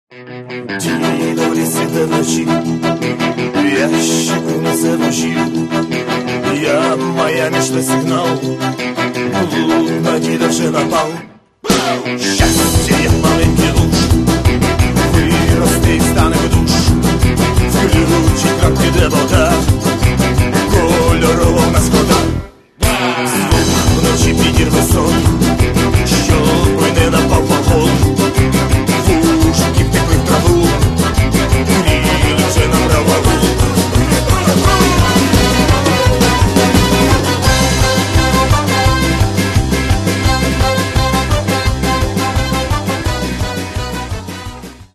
Каталог -> Рок та альтернатива -> Фолк рок